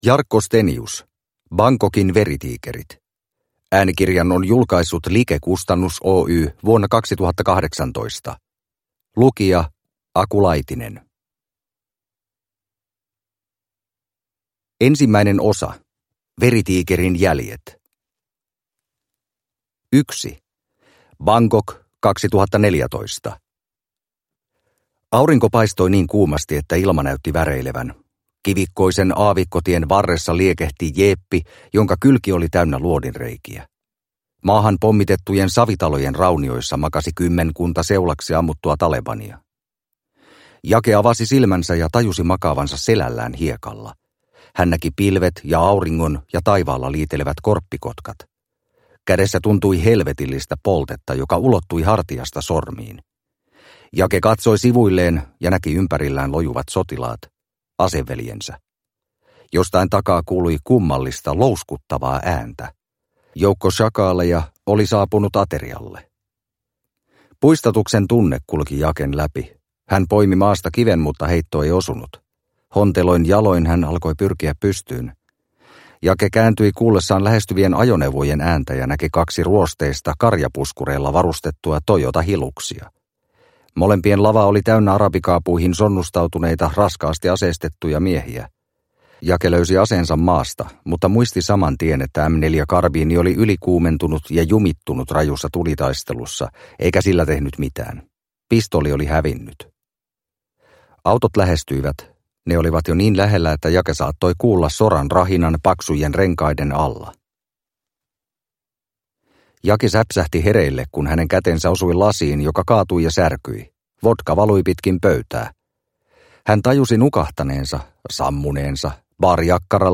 Bangkokin veritiikerit – Ljudbok – Laddas ner